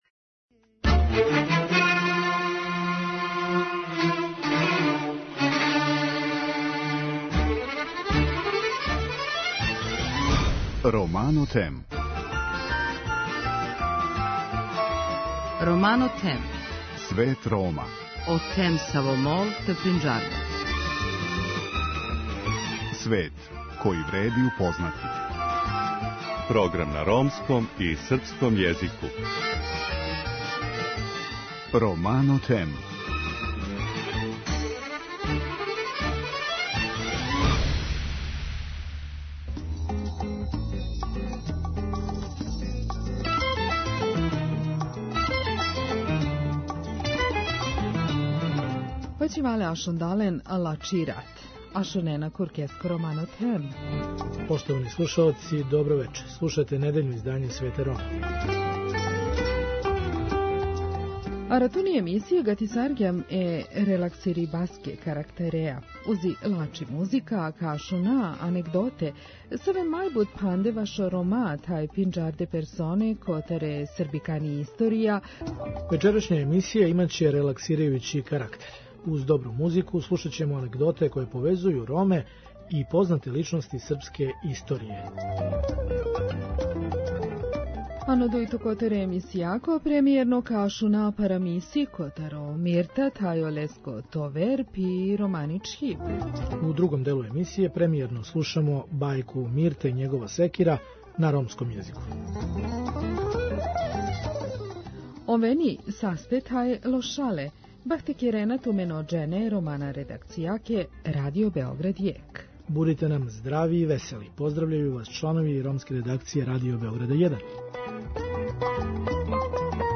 Вечерашња емисија имаће релаксирајући карактер. Уз добру музику, слушаћемо анегдоте које повезују Роме и познате личности српске историје, Кнеза Милоша Обреновића, Краља Петара И, војводу Степу Степановића, а ту је и анегдота о познатом атентатору на Адолфа Хитлера, Јохану Елзеру.